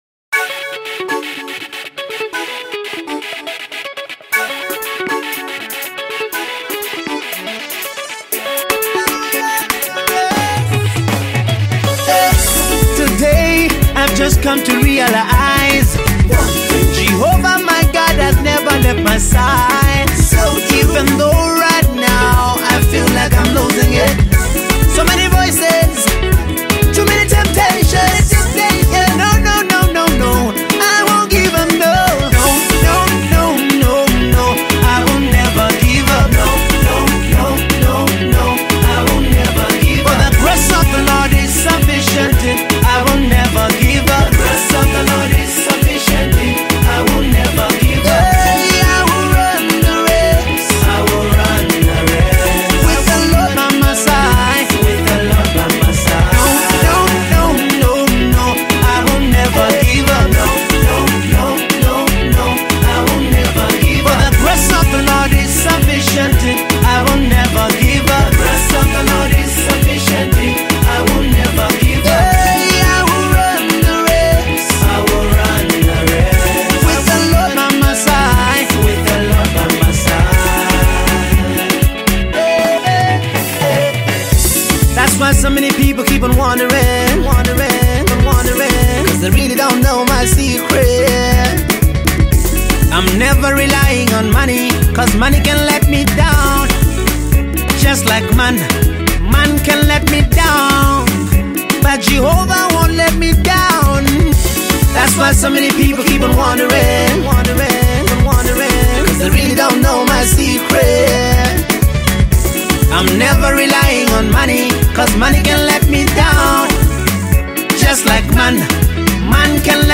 WORSHIP SONG
ZAMBIAN GOSPEL MUSIC